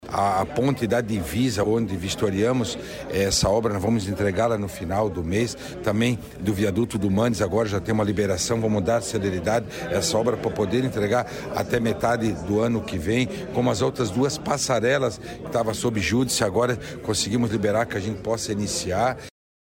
SECOM-Sonora-secretario-da-Infraestrutura-8.mp3